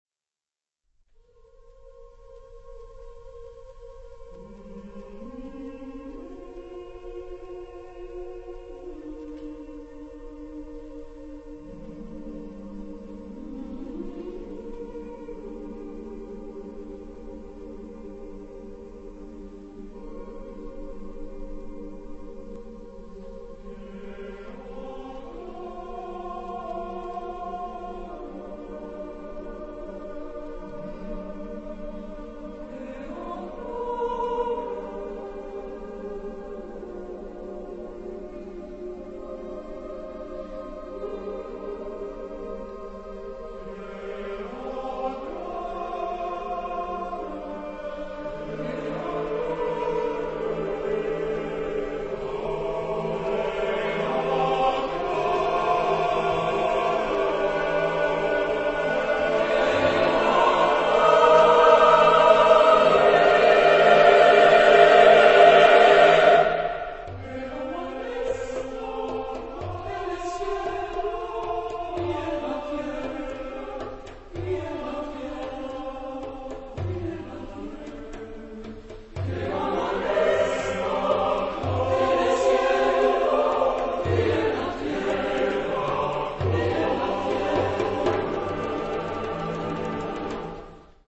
Genre-Style-Forme : Choral ; Madrigal ; Suite ; Profane
Type de choeur : SSAATTBB  (8 voix mixtes )
Solistes : SSATTB  (6 soliste(s))
Tonalité : modal